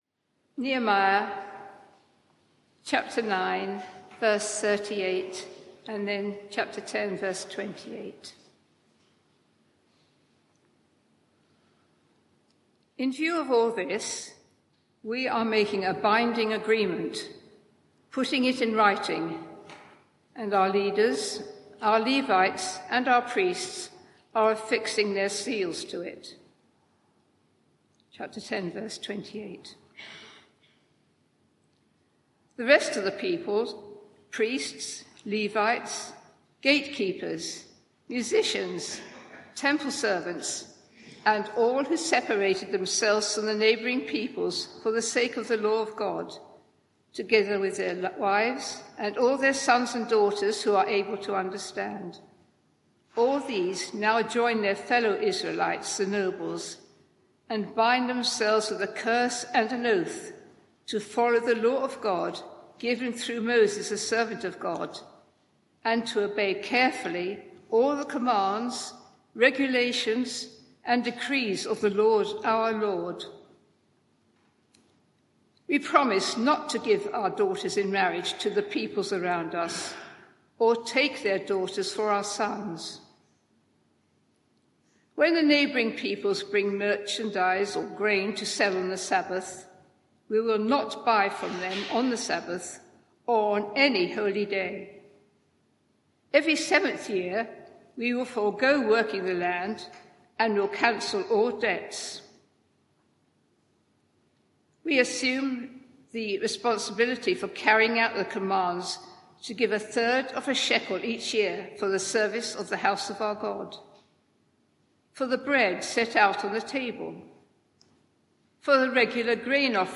Media for 11am Service on Sun 09th Jun 2024 11:00 Speaker
Sermon (audio)